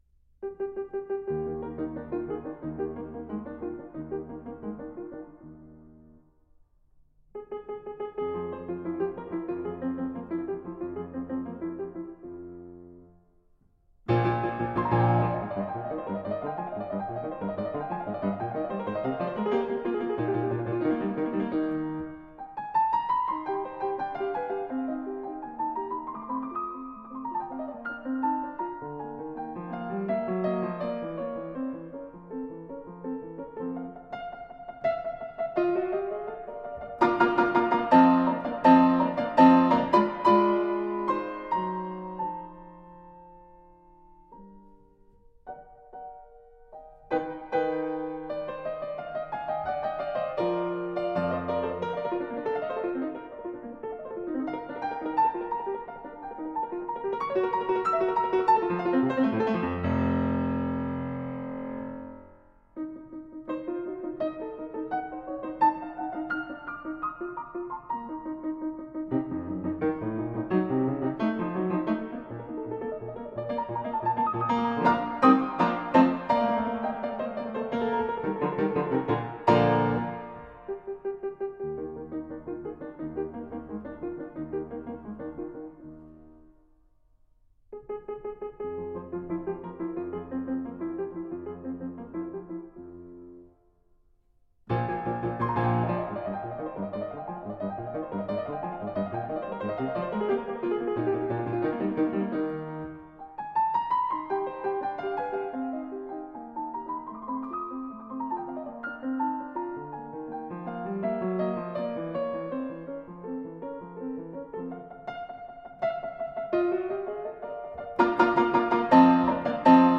Piano sonata
A sonata written for a solo piano.